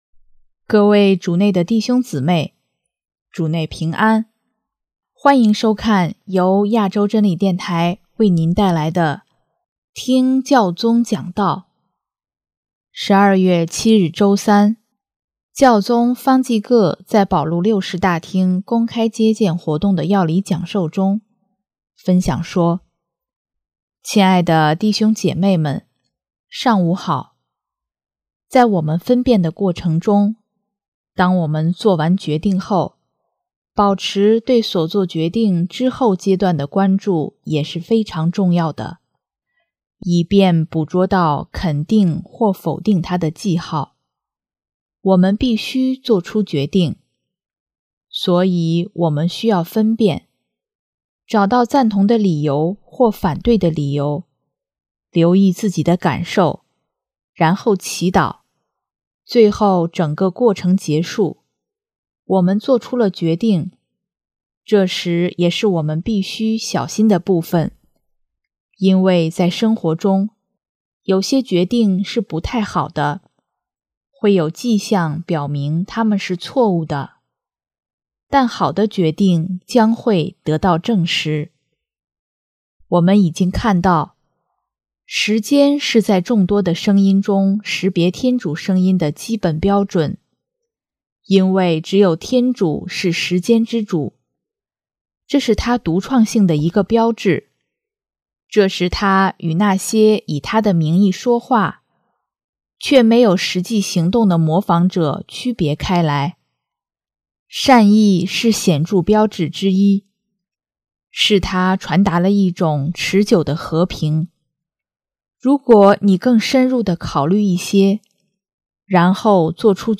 【听教宗讲道】|内心平安是肯定我们作出良好决定的记号【教理：分辨11】
12月7日周三，教宗方济各在保禄六世大厅公开接见活动的教理讲授中，分享说：